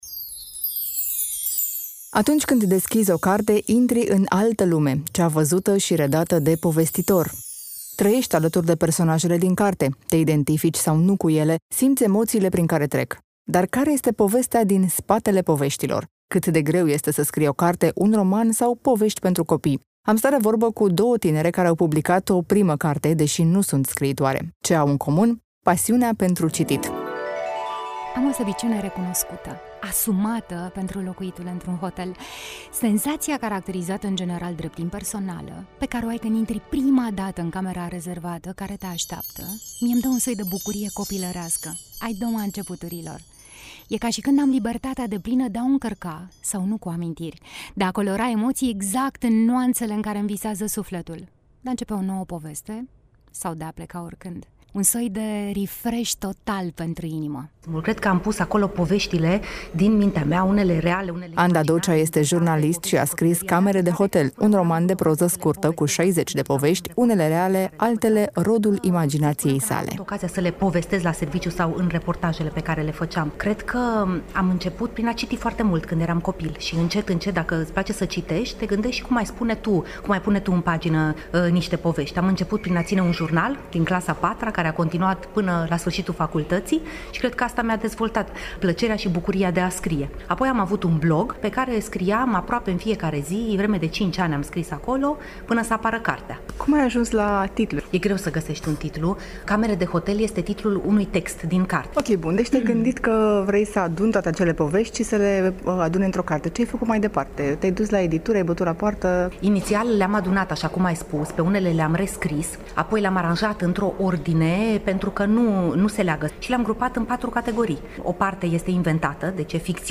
Am stat de vorbă cu două tinere care au publicat o primă carte, deşi nu sunt scriitoare.